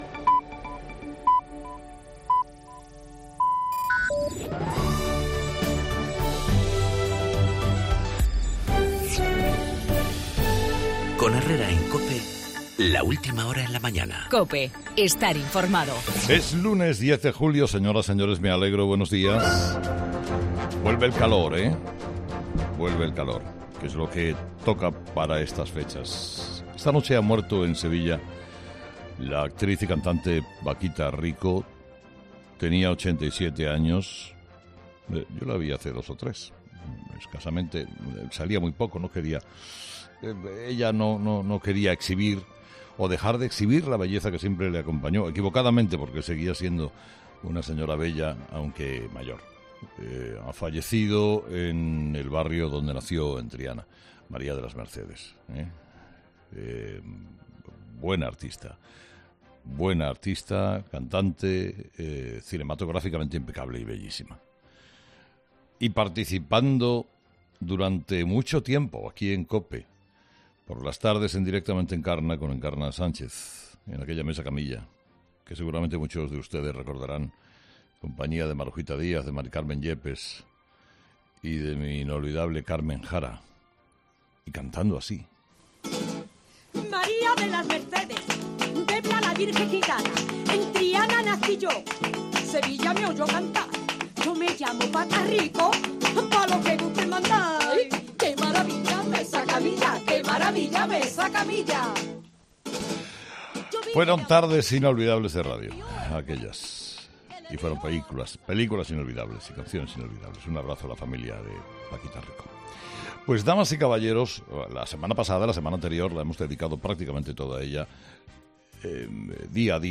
La muerte de Paquita Rico, el 20º aniversario del secuestro de Miguel Ángel Blanco, la salida de la cárcel de Leopoldo López y la reconquista de Mosul, en el monólogo de Carlos Herrera a las 8 de la mañana.